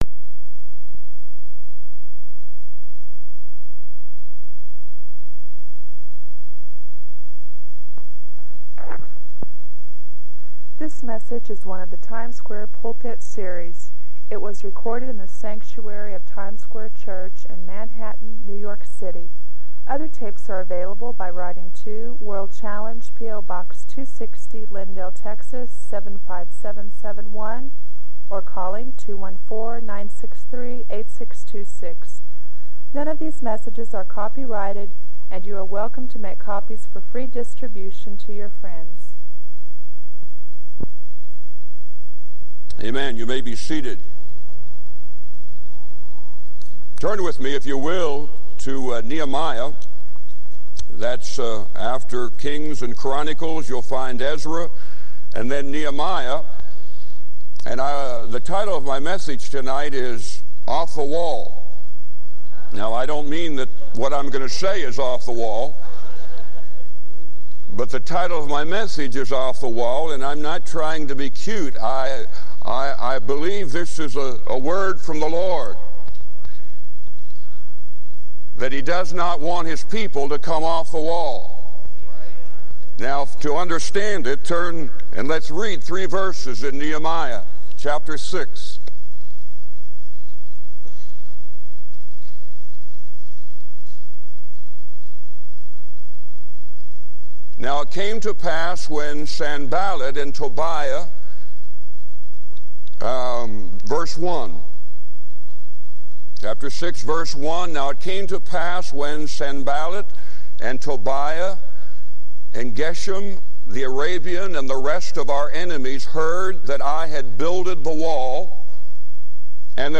This sermon challenges listeners to persevere and embrace the transformative work God is doing in their lives.